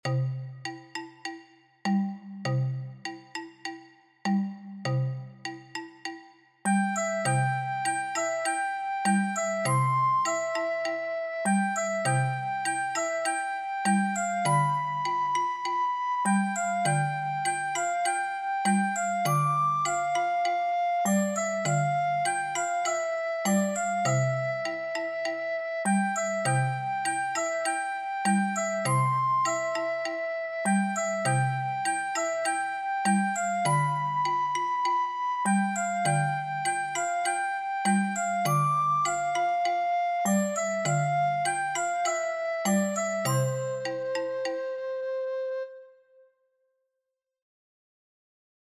Fíxate que hai 2 compases de introdución e logo a melodía comeza en anacruse.